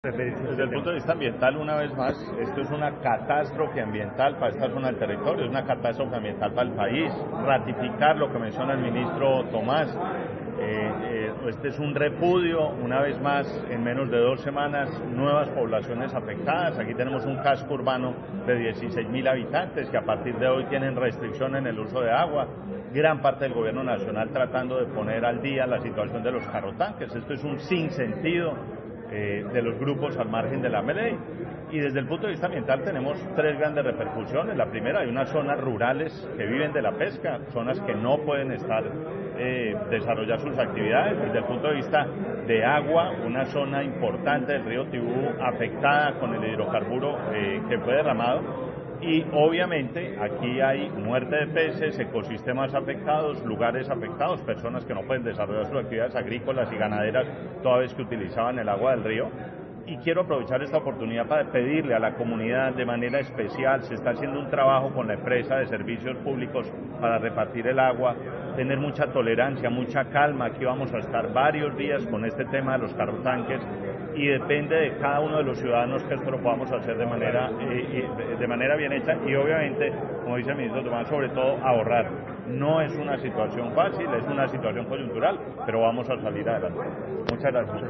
Declaraciones del Ministro de Ambiente y Desarrollo Sostenible, Gabriel Vallejo
18-VOZ_MINISTRO_TEORAMA.mp3